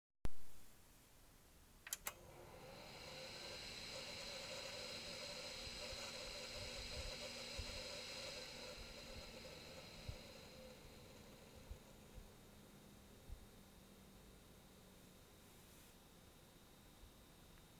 Neues Geräusch beim Booten
Parallel zum Hochdrehen der Lüfter ertönt ein gewisses "Knattern", was jedoch nicht von den Lüftern zu kommen scheint.
Zur Veranschaulichung lege ich ein Soundfile bei mit Aufzeichnung des Bootprozesses.